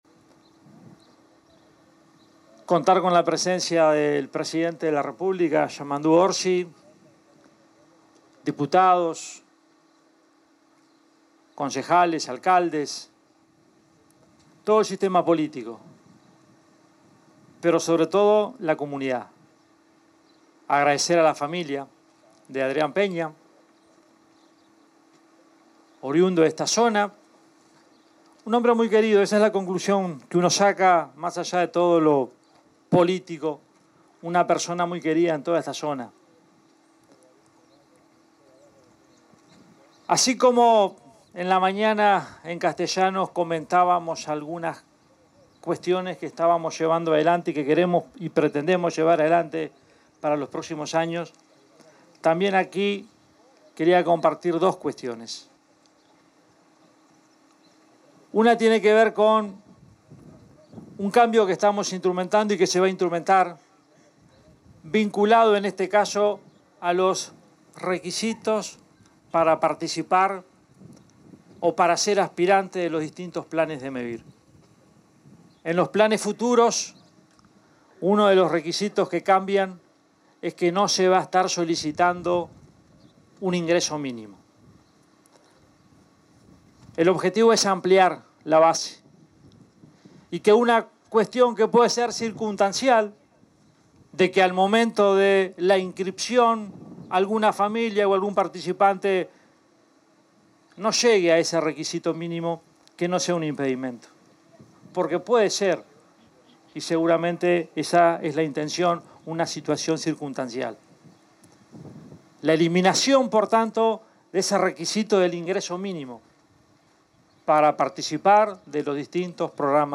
En ocasión de la entrega de 33 viviendas nuevas en la ciudad de San Bautista, departamento de Canelones, expresó un discurso el presidente de Mevir,